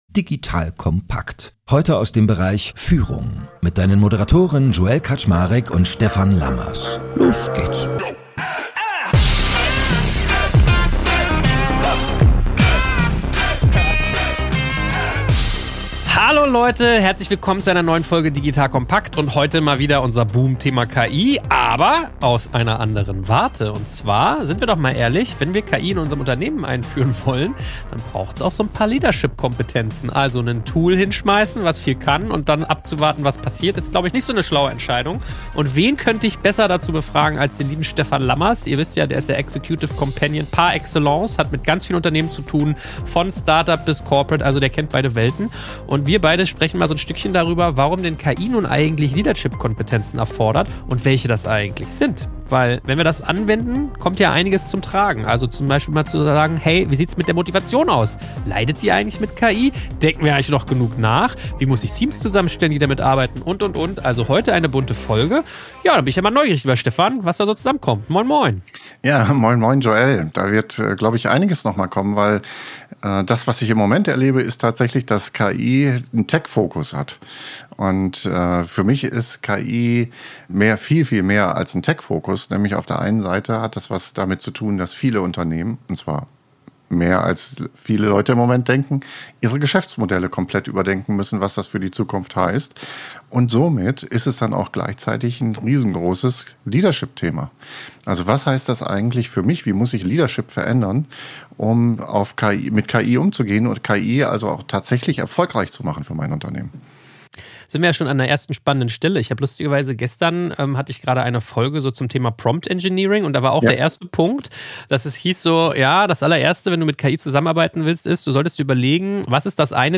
Ein inspirierendes Gespräch über die Chancen und Herausforderungen der digitalen Transformation, das zum Nachdenken anregt und neue Perspektiven eröffnet.